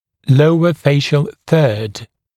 [‘ləuə ‘feɪʃl θɜːd][‘лоуэ ‘фэйшл сё:д]нижняя треть лица